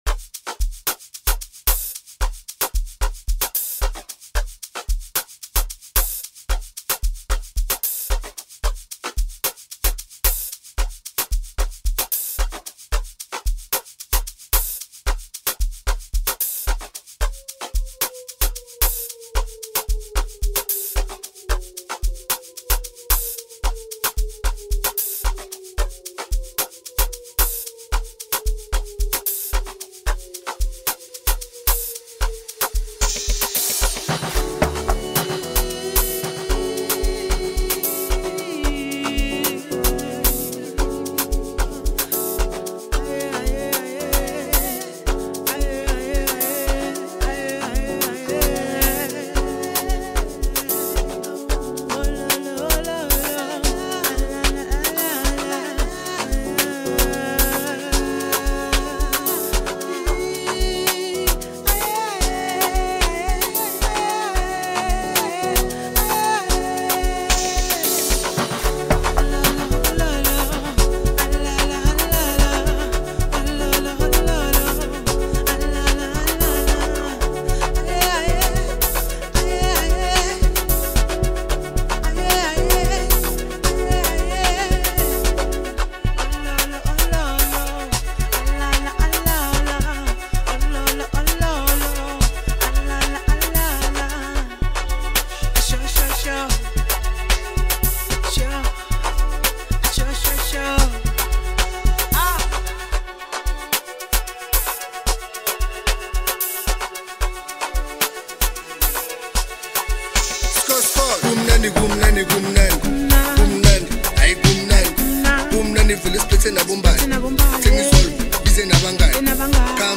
brings together a new Amapiano song